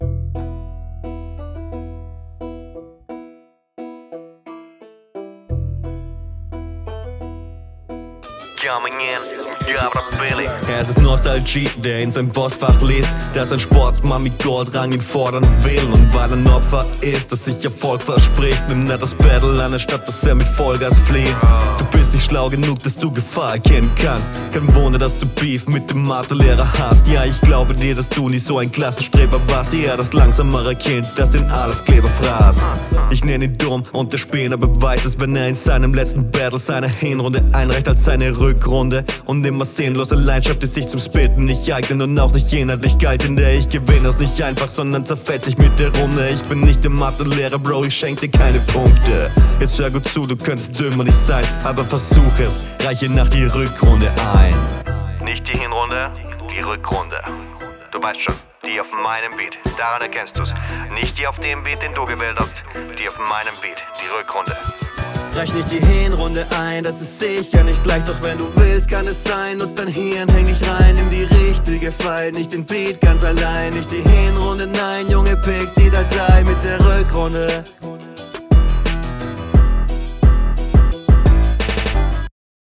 Flow gewöhnungsbedürftig, Hook dafür sehr nice
Flow kommt gut - vor Allem die Hook.